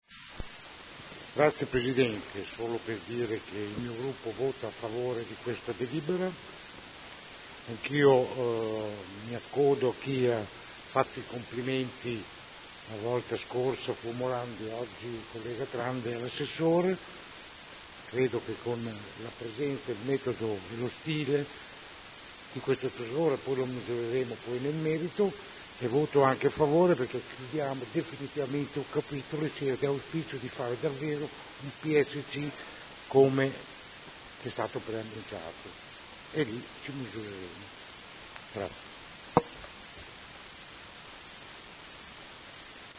Seduta del 22 ottobre. Proposta di deliberazione: Variante al Piano operativo comunale (POC) e al Regolamento urbanistico edilizio (RUE) – Controdeduzioni e approvazione ai sensi degli artt 33 e 34 della Legge regionale 20/2000 e s.m. Dichiarazione di voto